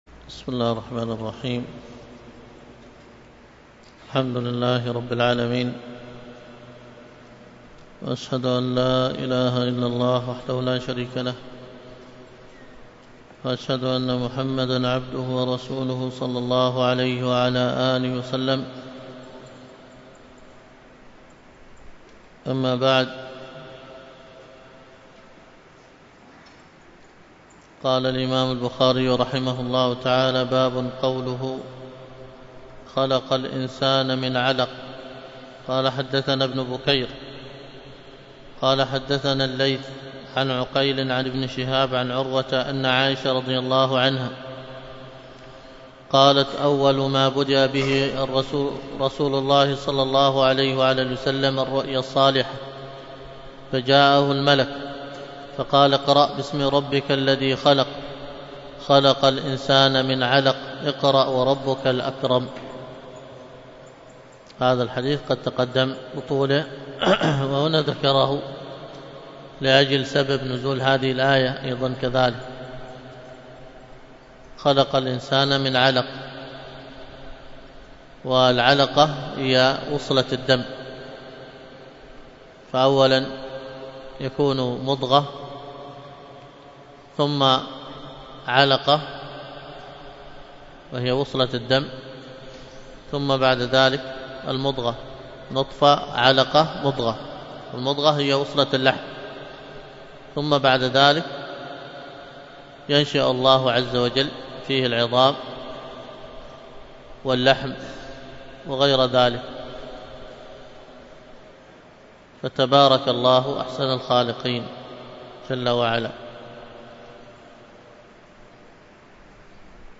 الدرس في كتاب التفسير من صحيح البخاري 300